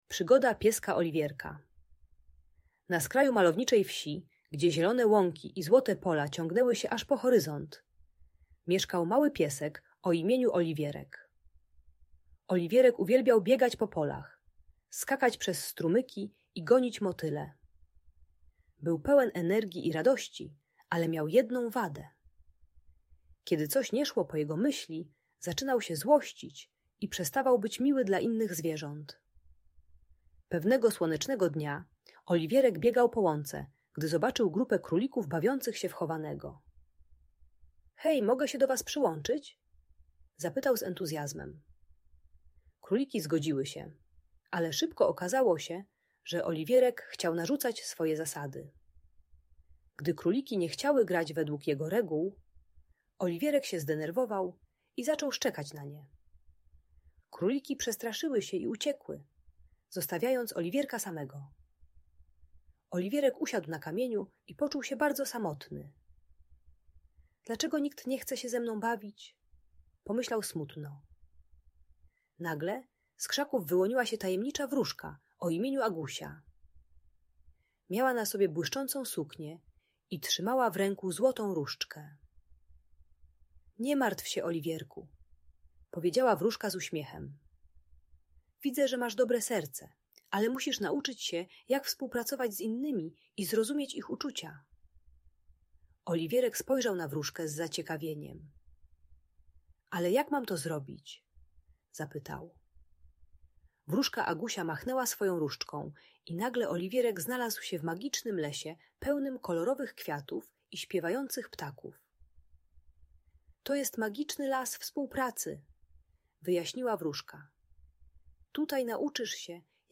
Przygoda Pieska Oliwierka - Audiobajka dla dzieci